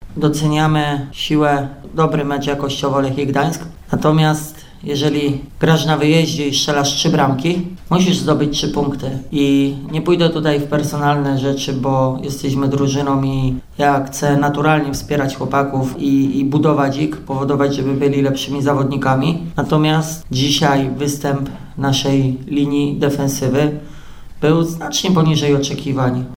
Komentarz trenera